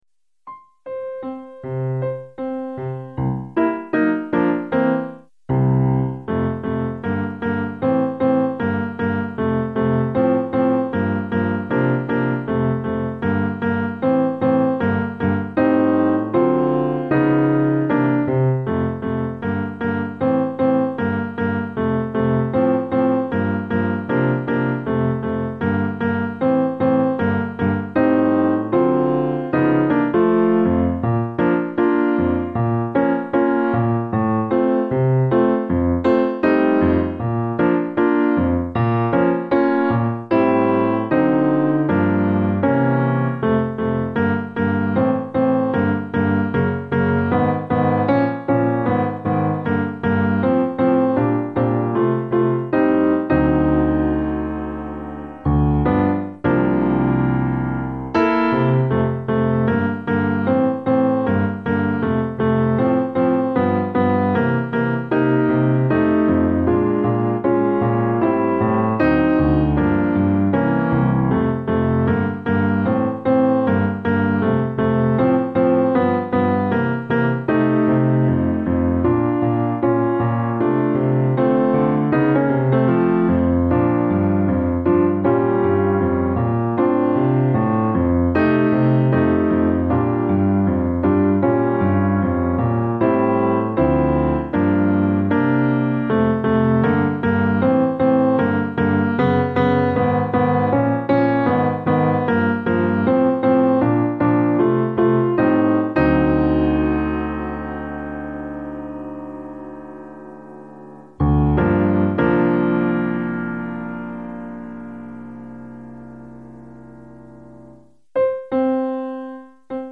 City Life Piano Medium Speed